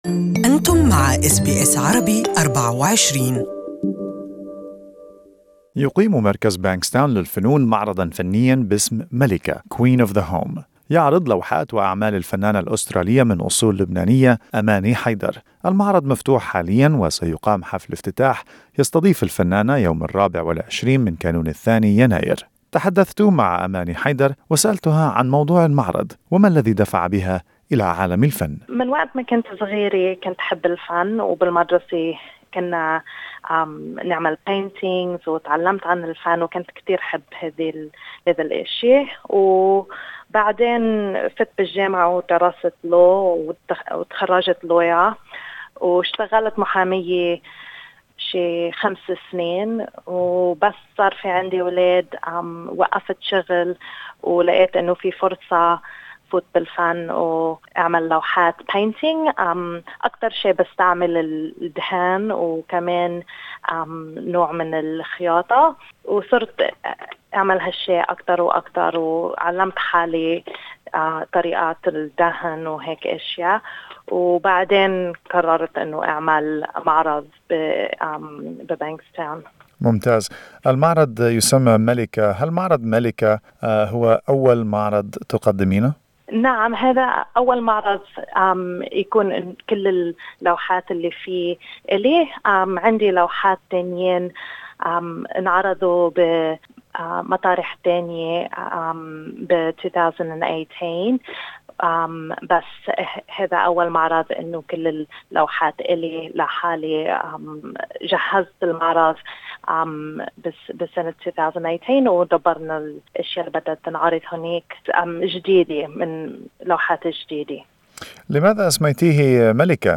تحدثنا مع الفنانة